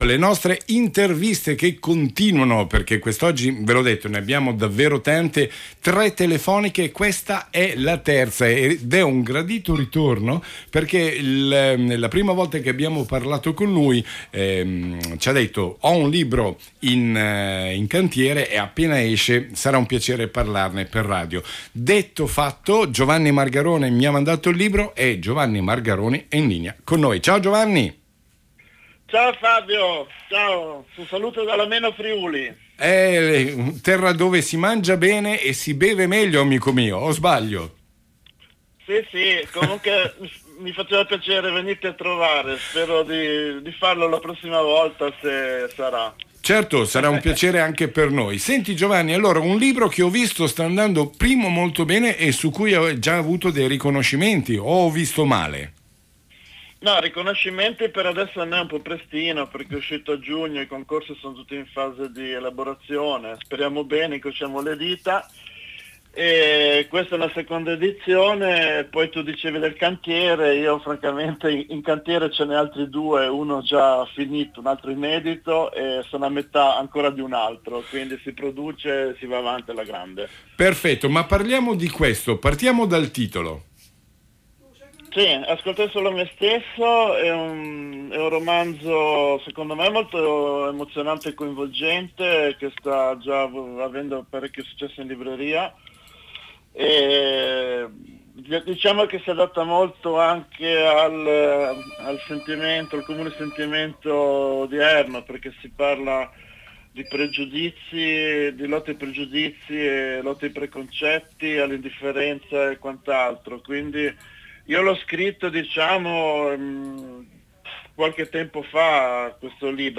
ECCO A VOI LA CLIP AUDIO DELL’INTERVISTA A RADIO CERNUSCO STEREO DEL 21 SETTEMBRE 2019